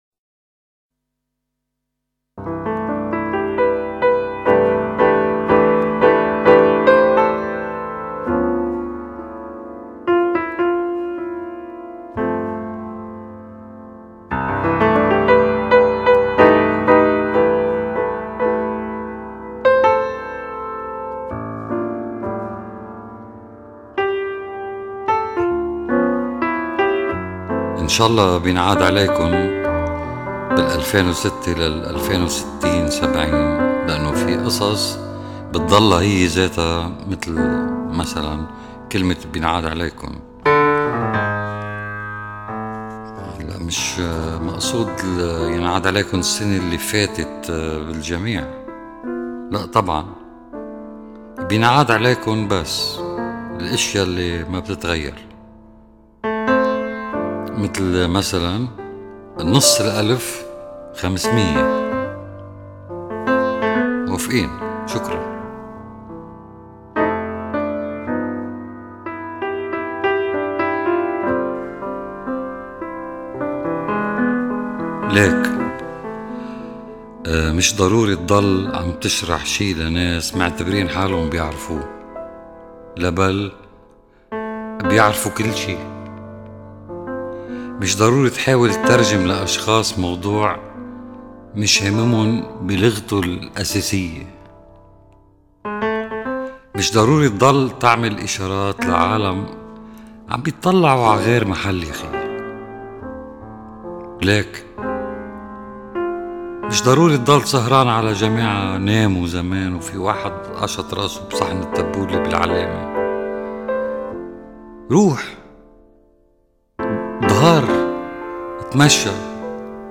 (1975-78) These sketches are a series of courageous, forthright and candid views on various aspects of the war. Both dramatic and humorous, they are acutely perceptive and free of political inhibitions and as such tell the story of the war up until the Israeli invasion with little necessary supportive documentation. The following are digitized from 8 tapes recorded in 1975 and 1976.